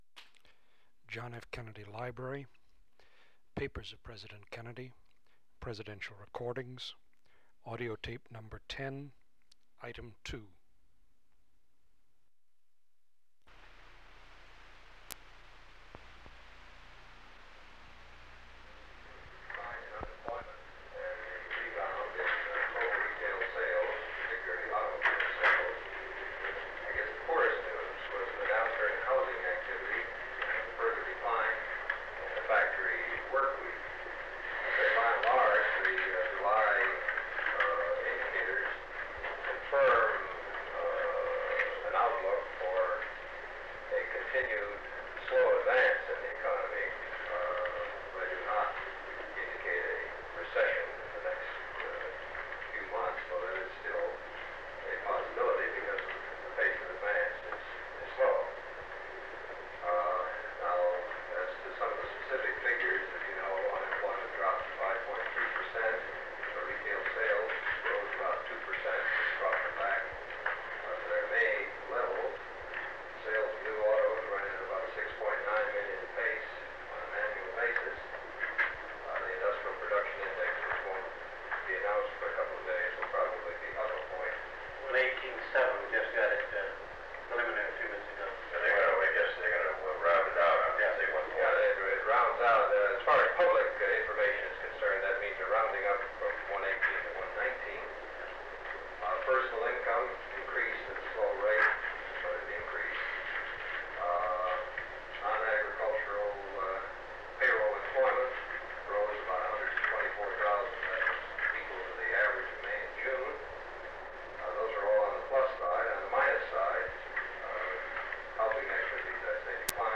Secret White House Tapes | John F. Kennedy Presidency Meeting on the Tax Cut Proposal Rewind 10 seconds Play/Pause Fast-forward 10 seconds 0:00 Download audio Previous Meetings: Tape 121/A57.